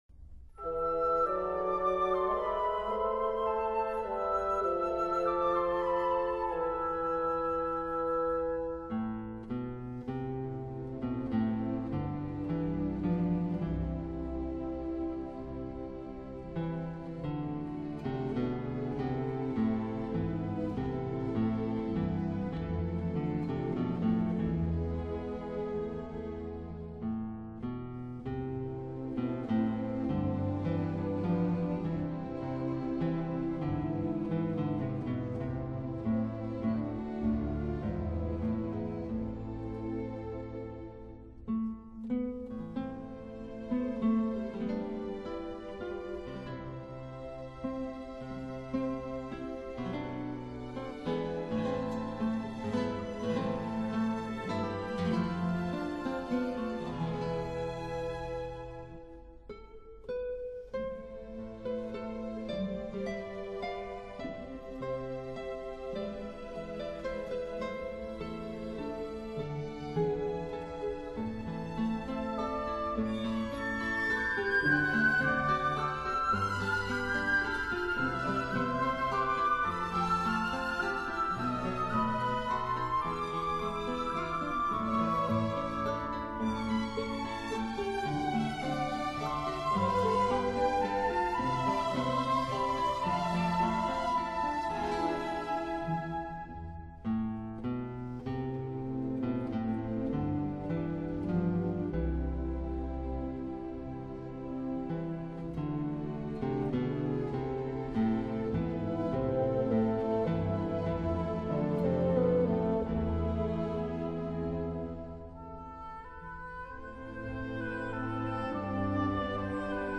guitar